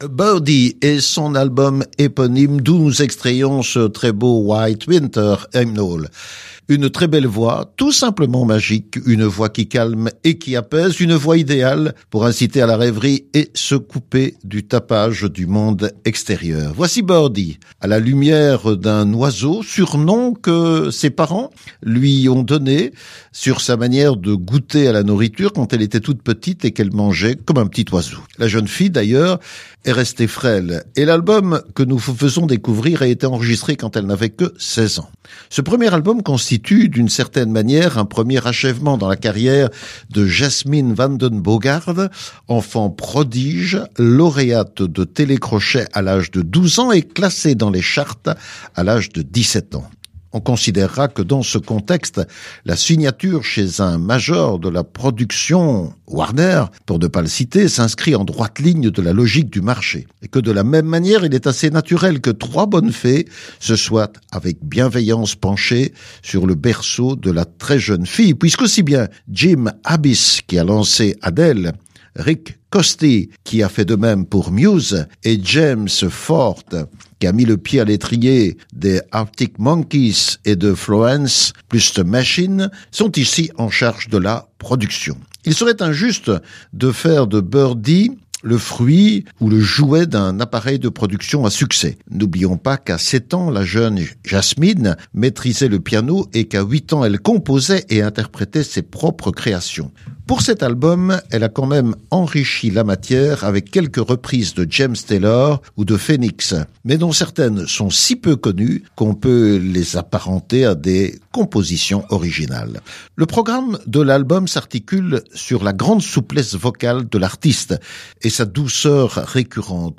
chanteuse folk britannique